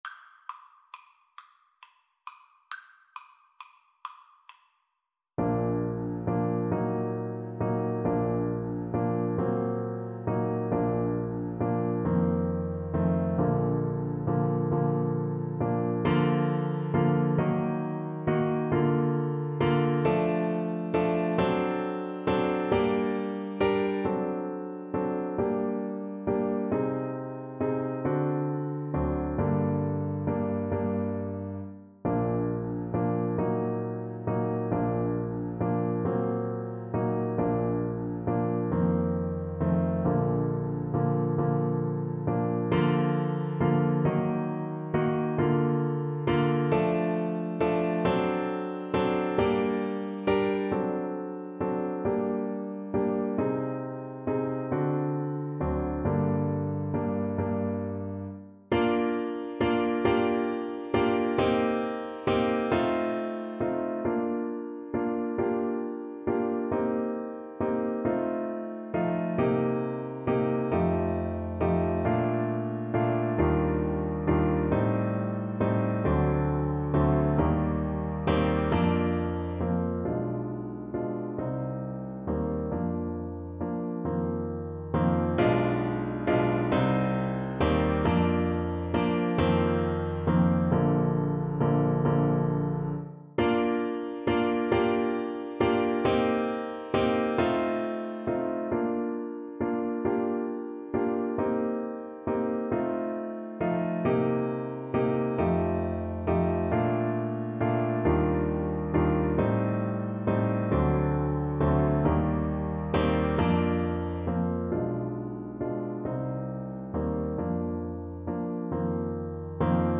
Play (or use space bar on your keyboard) Pause Music Playalong - Piano Accompaniment Playalong Band Accompaniment not yet available transpose reset tempo print settings full screen
Bassoon
Bb major (Sounding Pitch) (View more Bb major Music for Bassoon )
6/8 (View more 6/8 Music)
Andantino .=c.45 (View more music marked Andantino)
Classical (View more Classical Bassoon Music)